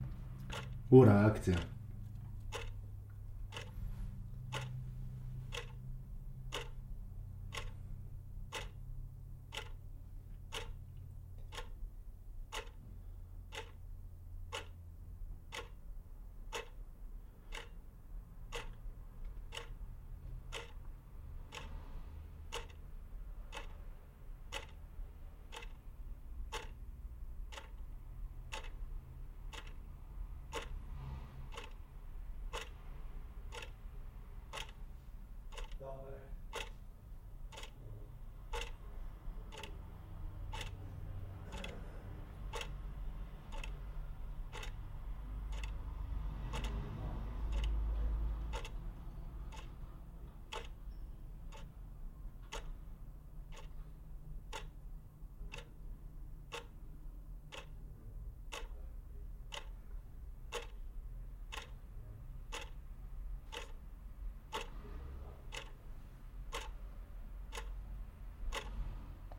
时钟滴答作响
描述：但是壁炉架上的时钟，滴答声！